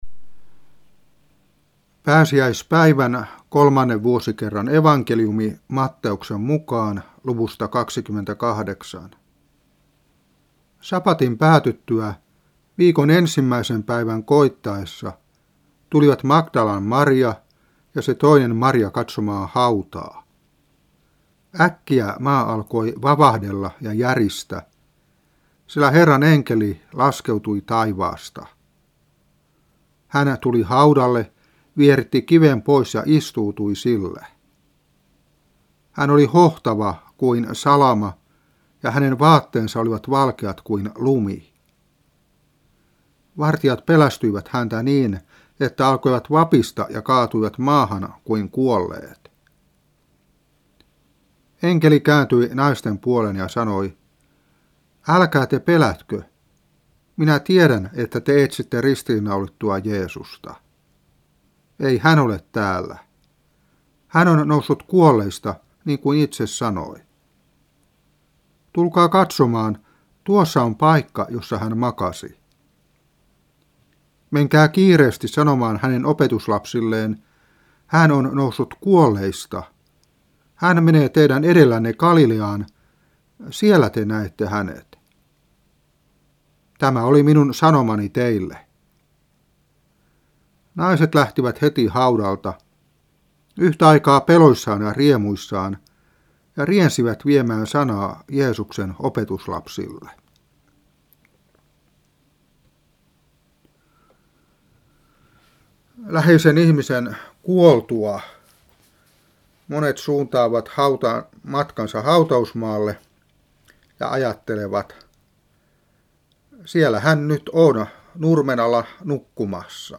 Saarna 1995-4.